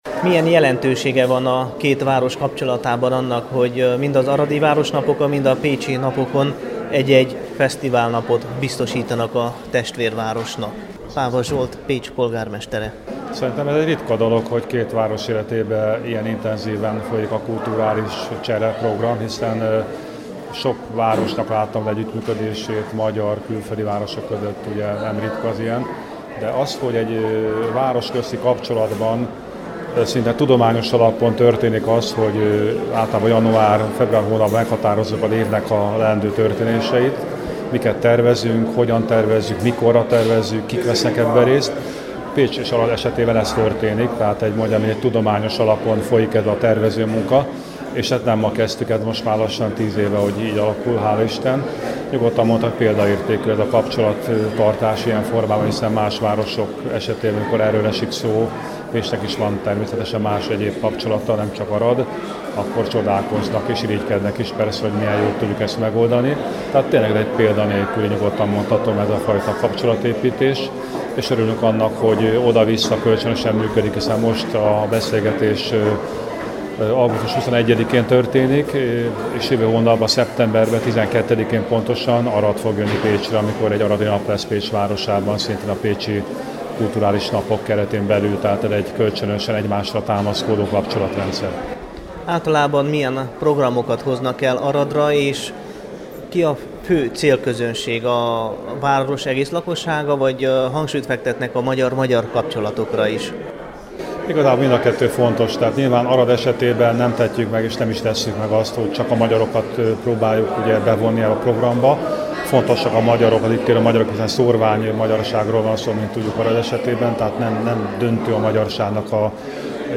készített összeállítást a Temesvári Rádió számára
Pecs_Napja_Aradon_2014.mp3